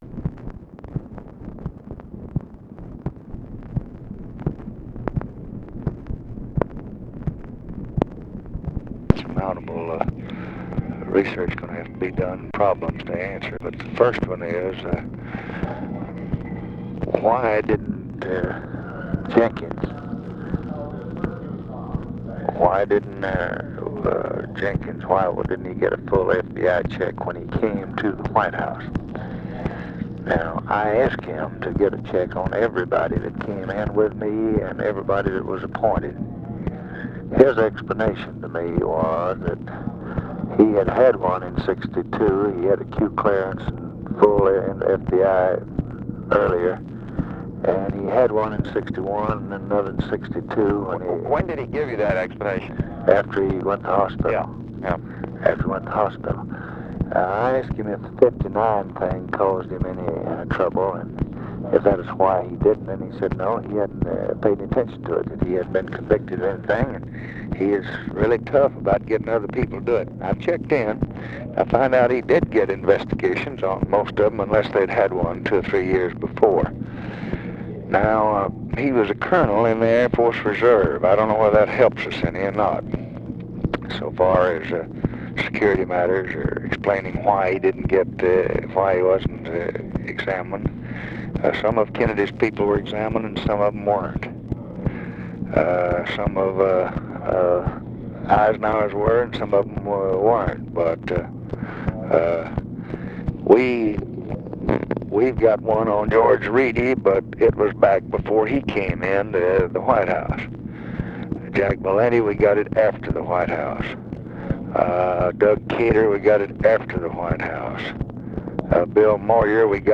Conversation with ROBERT MCNAMARA, October 19, 1964
Secret White House Tapes